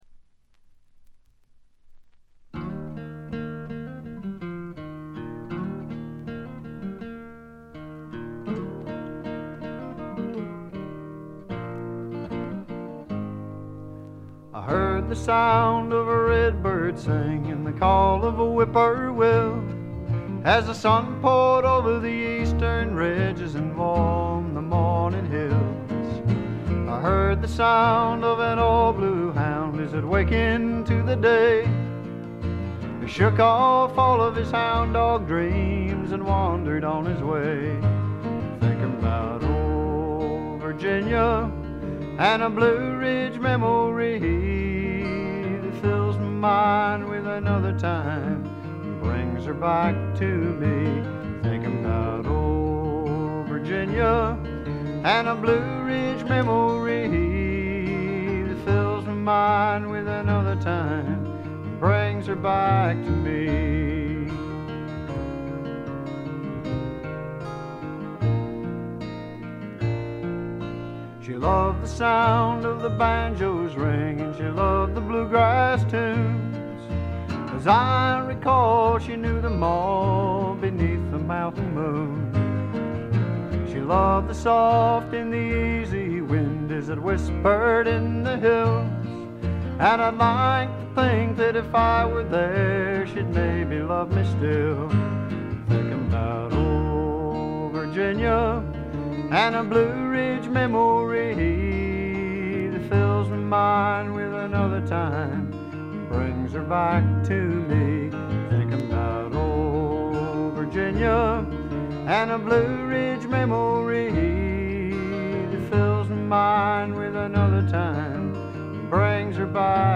ほとんどノイズ感無し。
内容的にも前作路線で弾き語りに近いシンプルなバックのみで歌われる全14曲。
ロンサムで暖かい空気が部屋の中で静かに満ちていくような感覚がたまらないです。
試聴曲は現品からの取り込み音源です。